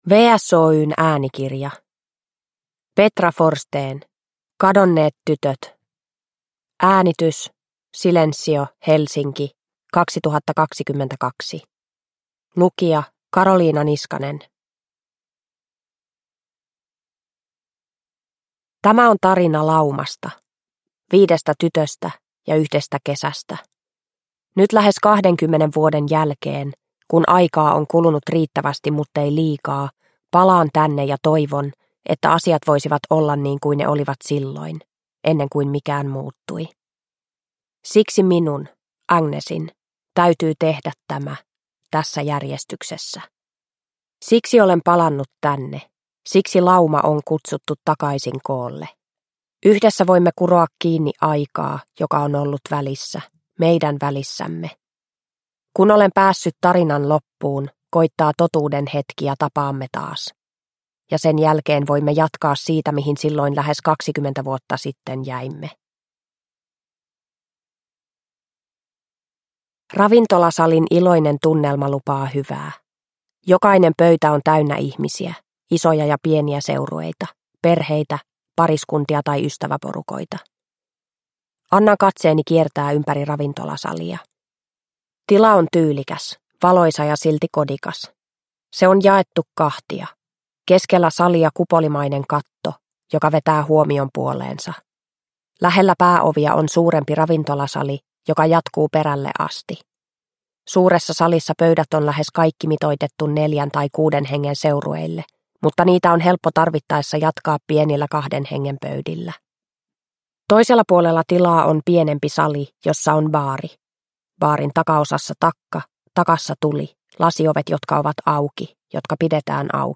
Kadonneet tytöt – Ljudbok – Laddas ner